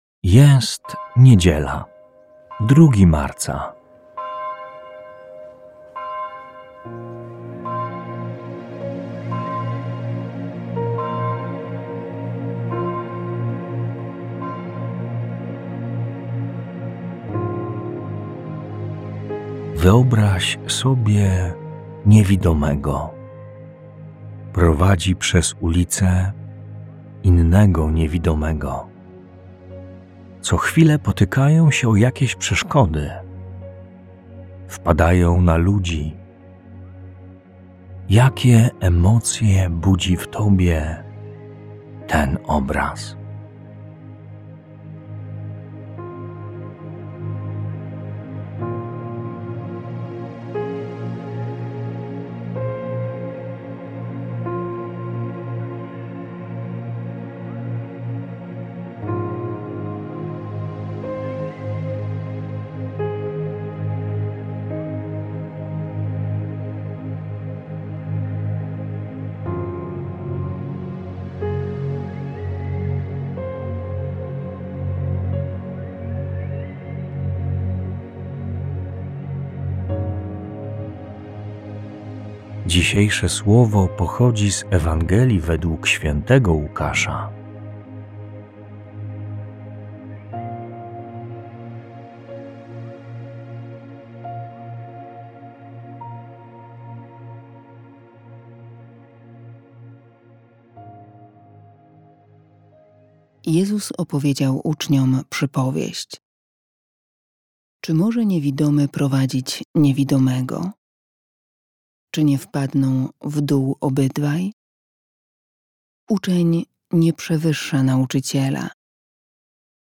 Polscy Jezuici, idąc za przykładem swoich braci z Wielkiej Brytanii, zaproponowali serie około dziesięciominutowych rozważań łączących muzykę i wersety z Biblii, pomagające odkrywać w życiu Bożą obecność i pogłębić relacje z Bogiem.